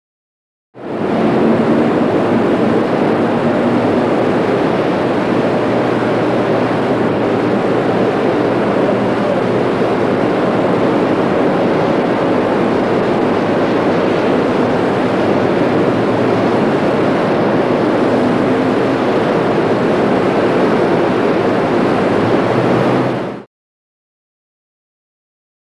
Generator; Large & Constant; Generator Roar. Constant And Large. Interior.